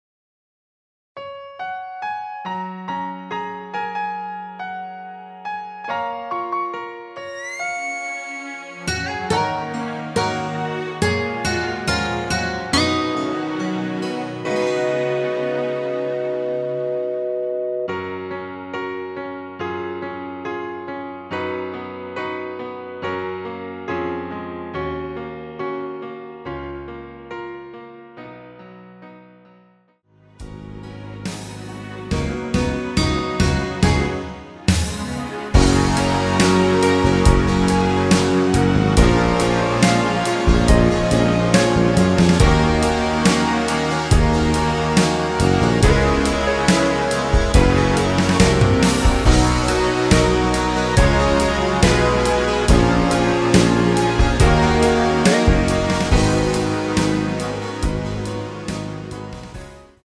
*부담없이즐기는 심플한 MR
F#
앞부분30초, 뒷부분30초씩 편집해서 올려 드리고 있습니다.
중간에 음이 끈어지고 다시 나오는 이유는